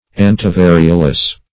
Antivariolous \An`ti*va*ri"o*lous\, a. Preventing the contagion of smallpox.